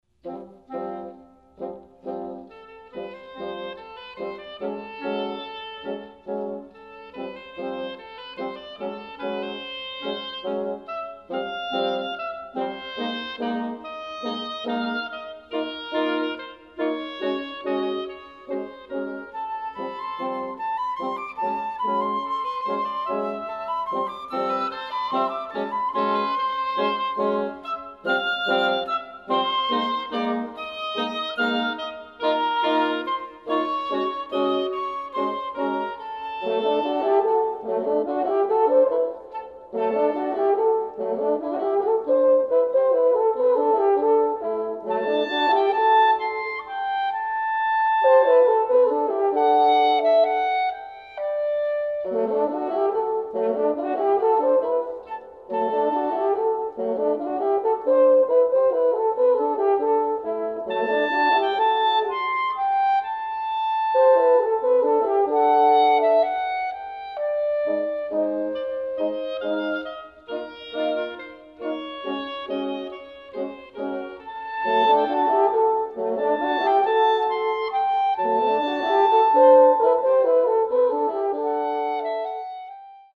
FOUR SCENES FOR WOODWIND QUINTET